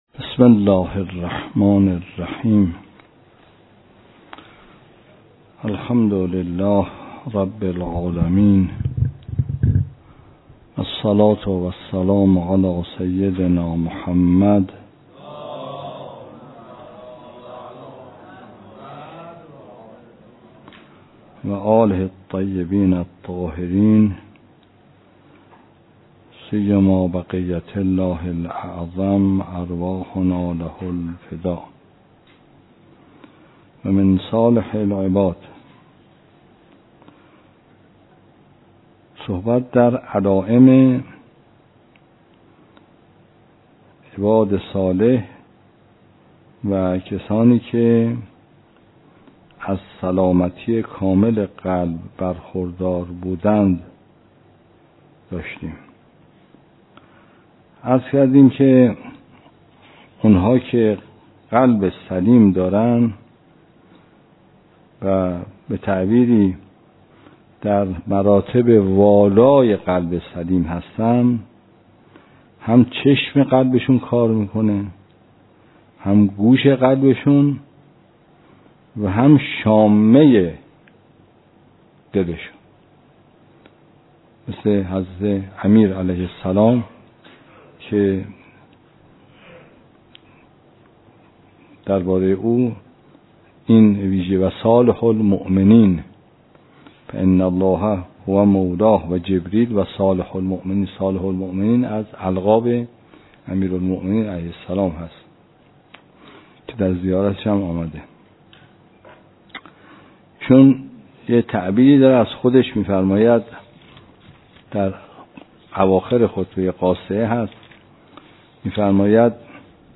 درس خارج فقه